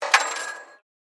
Звуки для игр
Звук сбора монет в игровом процессе